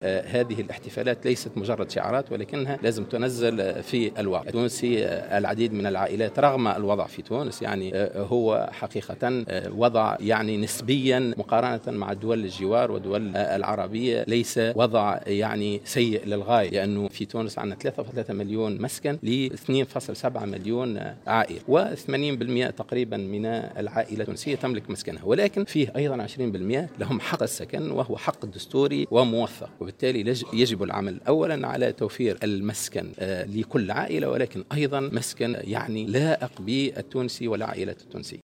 وأضاف السالمي في تصريح للجوهرة اف ام على هامش ملتقى انتظم بالعاصمة ، بمناسبة الاحتفال باليوم العالمي والعربي للإسكان، أن الوضع السكني في تونس مقارنة بدول الجوار وبالدول العربية ليس سيئا للغاية.